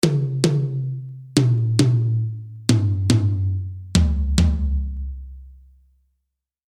Attackbetonter Sound mit tiefem und kontrolliert ausklingenden Sustain
Durch das leicht tiefer gestimmte Schlagfell erlangt man einen etwas satteren Attack als bei gleich hoch gestimmten Schlag- und Reonanzfellen.
dt_tom02.mp3